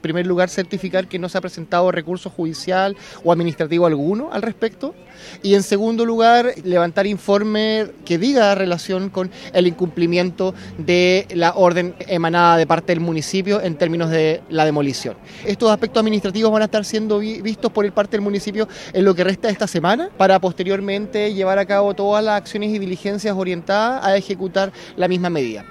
El alcalde subrogante de Valdivia, el administrador municipal, Cristian Oñate, manifestó que ahora, como casa edilicia, deben cautelar distintos aspectos jurídicos y administrativos estos días, para hacer cumplir lo resuelto (la demolición).